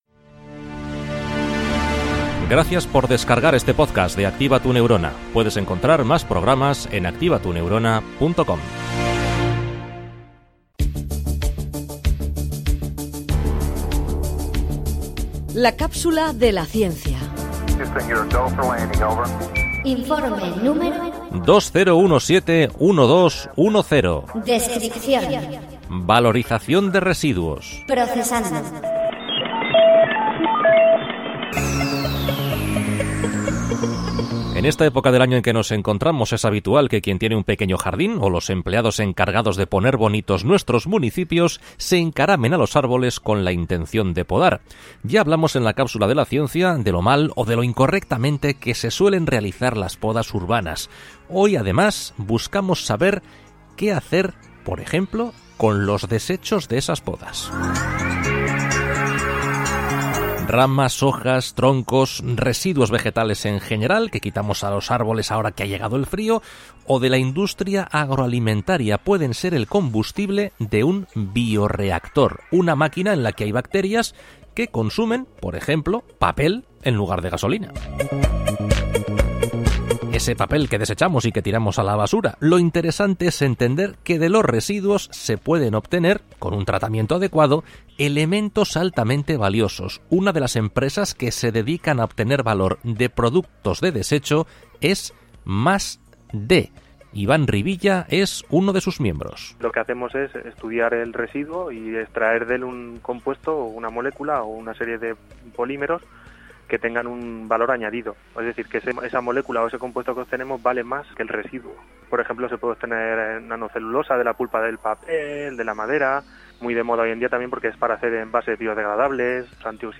La Cápsula de la Ciencia ® es un microespacio de ciencia con trasfondo social producido por Activa Tu Neurona.
La comunicación de la ciencia es la protagonista de 3 minutos de radio en los que colaboramos con científicos punteros para contar de forma amena y sencilla los resultados de sus últimas investigaciones.